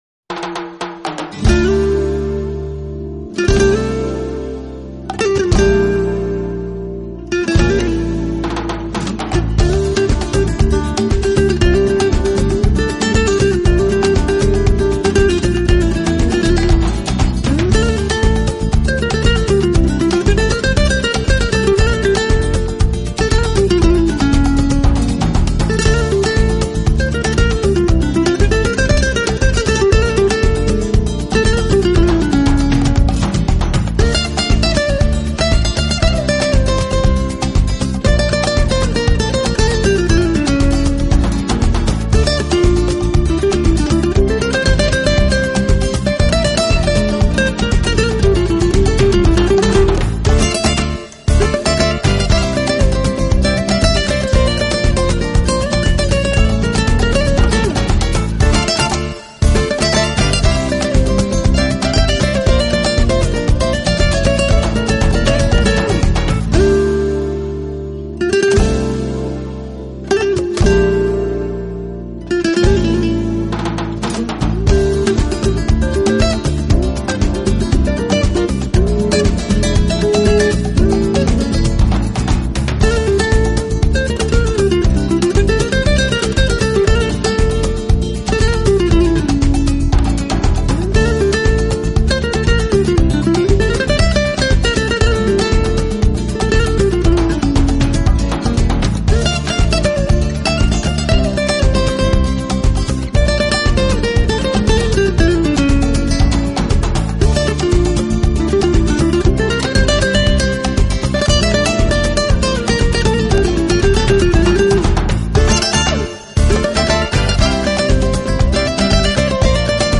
Genre: New Age, Relax, Instrumental